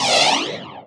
powerupX.mp3